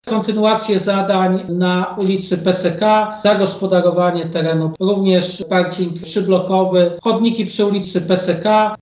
Informował o tym Prezydent Stalowej Woli Lucjusz Nadbereżny.